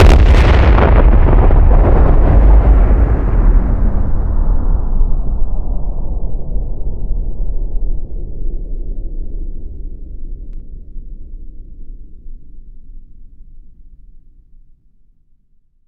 SmartbombPhoton01b.ogg